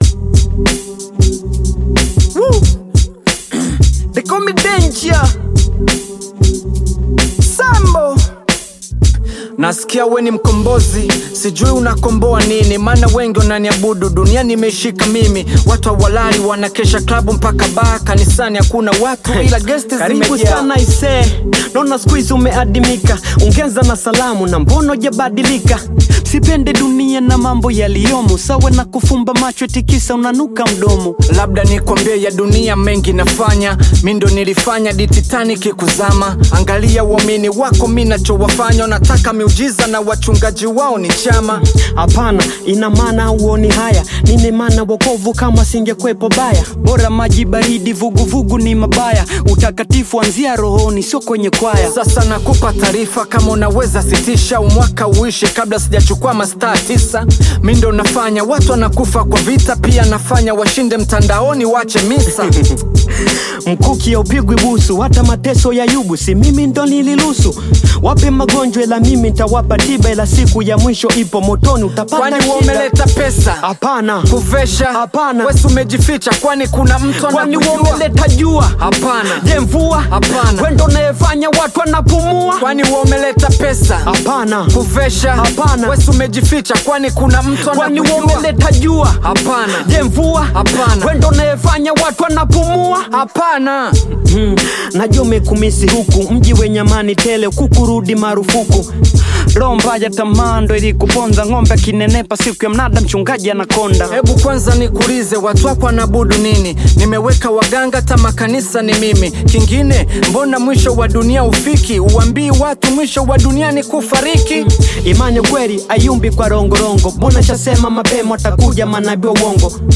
Tanzanian hip‑hop single